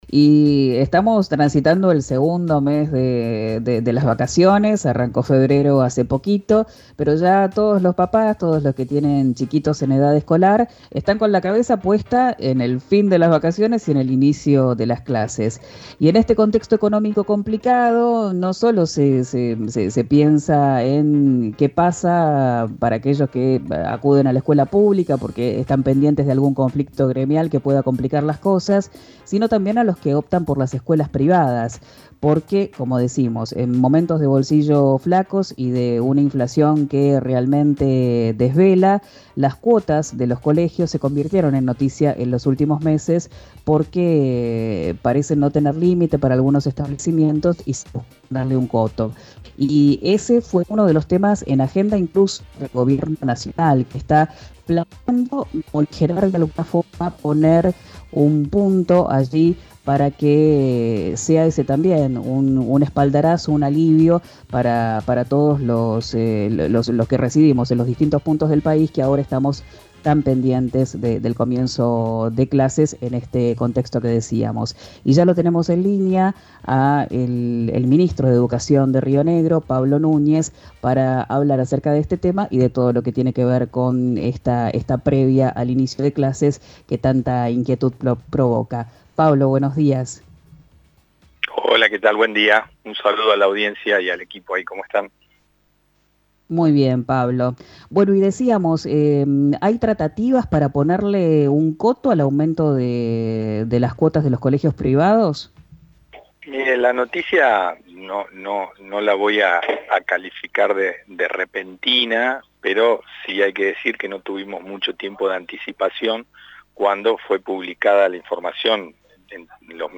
Lo dijo el ministro de Educación y Derechos Humanos Pablo Núñez en diálogo con RÍO NEGRO RADIO.